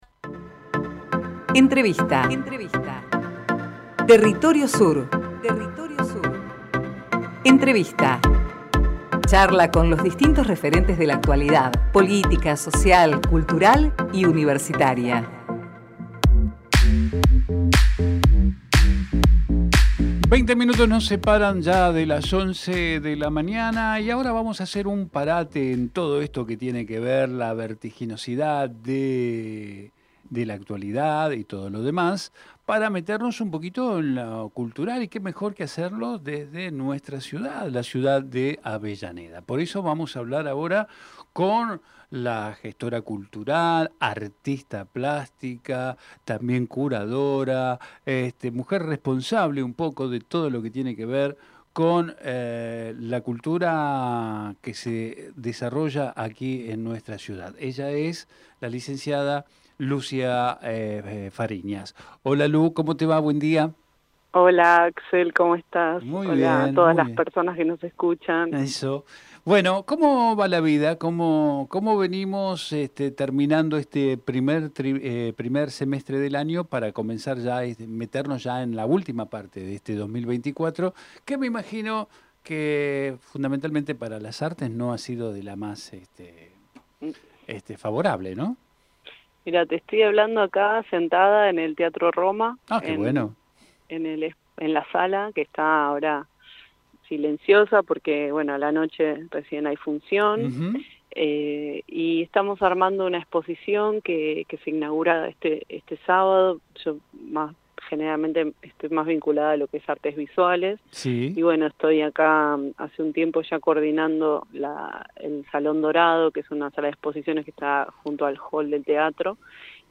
Compartimos la entrevista realizada en "Territorio Sur"